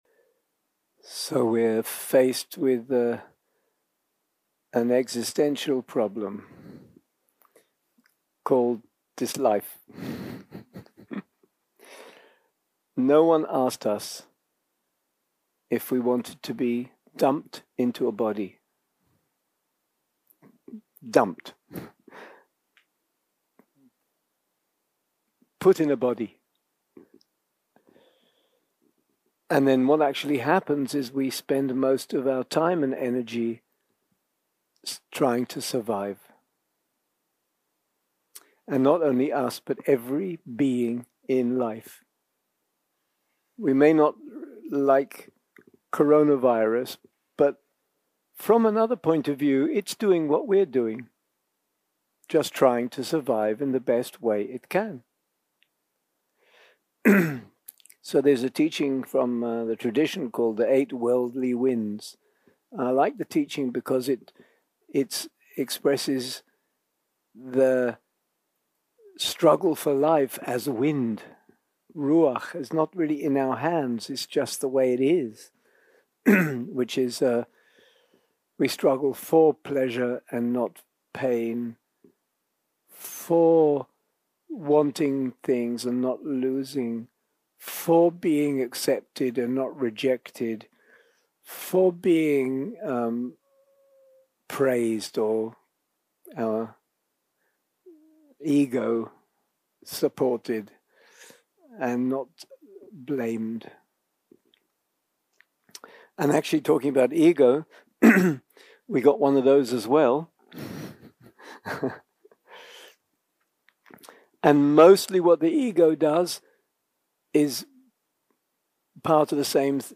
יום 5 - הקלטה 12 - ערב - שיחת דהרמה - Living with kindness is living well
יום 5 - הקלטה 12 - ערב - שיחת דהרמה - Living with kindness is living well 0:00 0:00 סוג ההקלטה: Dharma type: Dharma Talks שפת ההקלטה: Dharma talk language: English